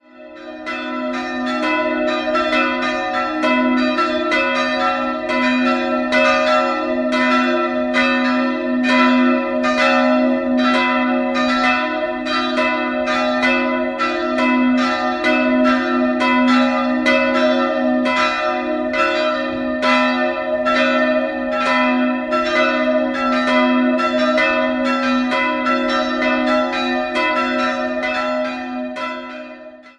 Altäre und die Ausgestaltung der Kirche sind barock. 3-stimmiges TeDeum-Geläute: c''-es''-f'' Die Glocken wurden im Jahr 1946 von Karl Hamm in Regensburg gegossen.